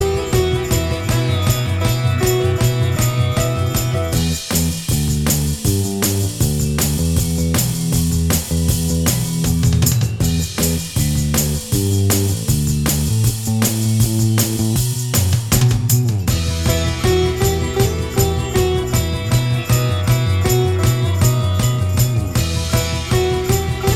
Minus Guitars Rock 4:00 Buy £1.50